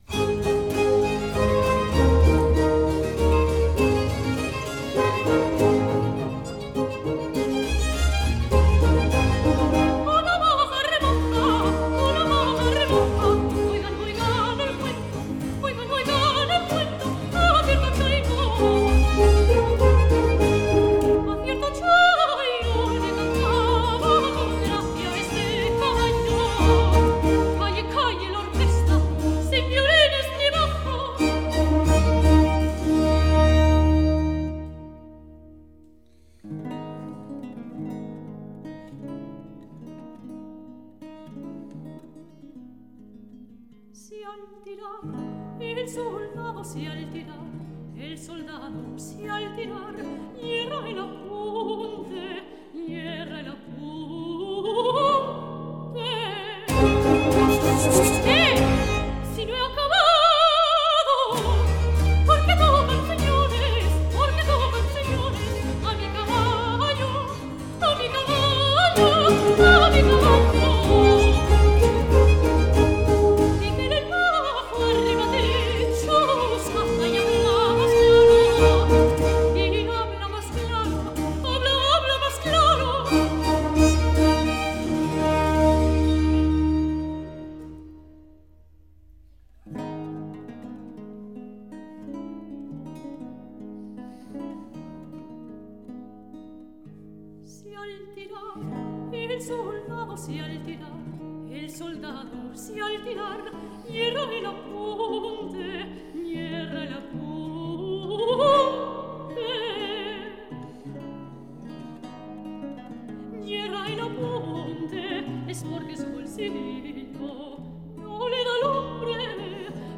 soprano
8_la_sale_mi_guitarra_no__3_seguidillas_allegretto.mp3